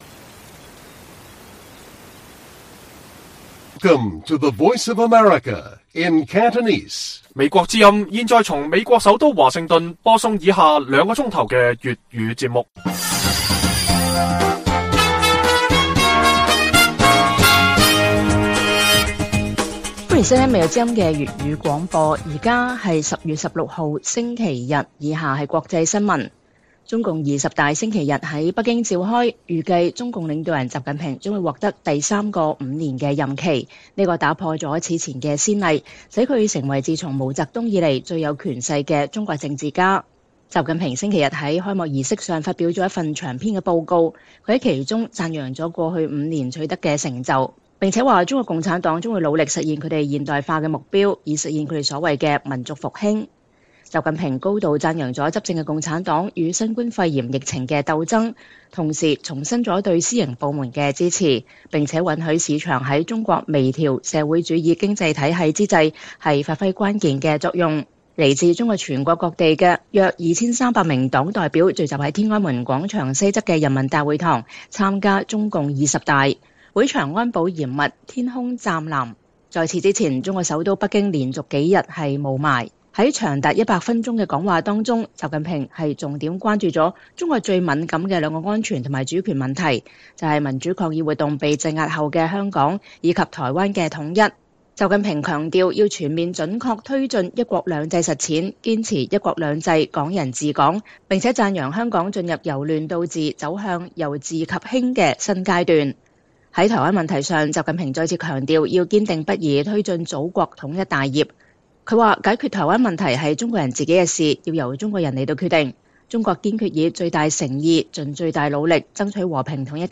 粵語新聞 晚上9-10點: 中共二十大召開 預計習近平將連任總書記5年